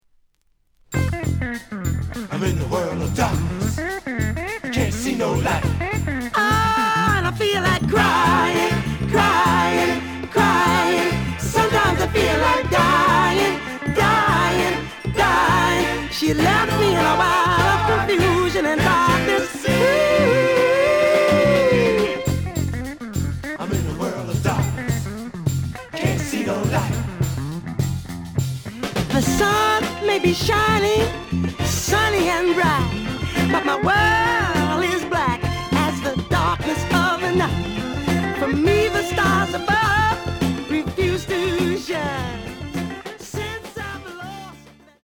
The audio sample is recorded from the actual item.
●Format: 7 inch
●Genre: Soul, 70's Soul